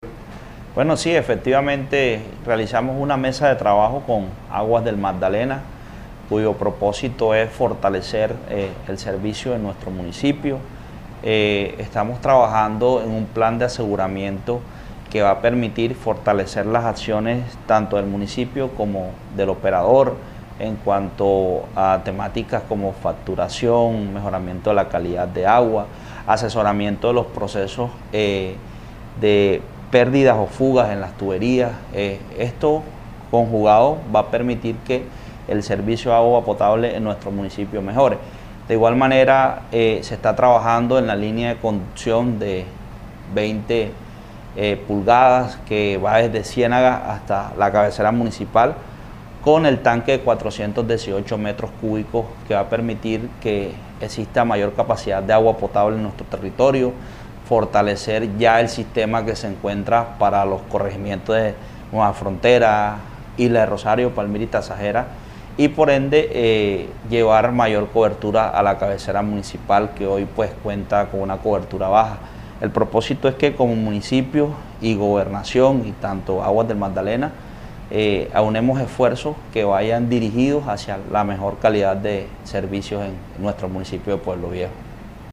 FABIAN-OSPINO-ALCALDE-PUEBLOVIEJO.mp3